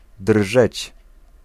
Ääntäminen
Synonyymit pervibrer Ääntäminen France: IPA: [vi.bʁe] Haettu sana löytyi näillä lähdekielillä: ranska Käännös Ääninäyte 1. drżeć Määritelmät Verbit (physique) Exécuter des vibrations .